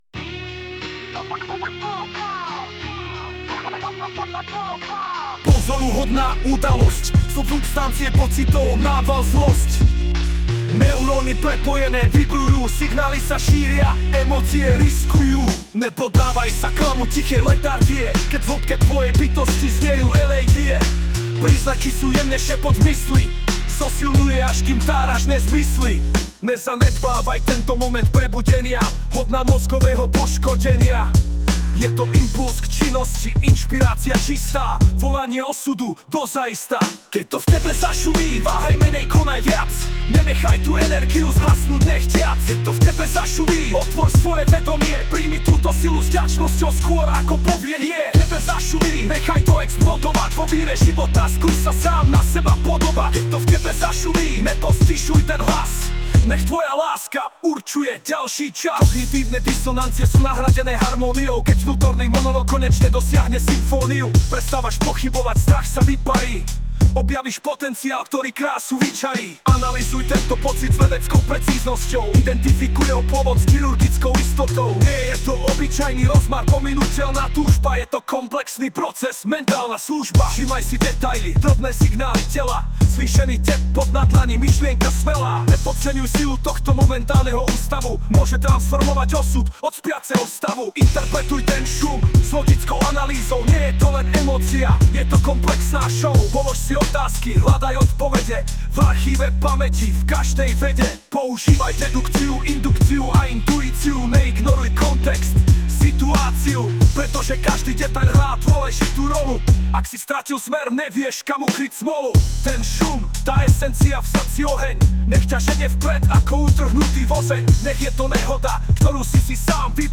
Anotace: nočný rap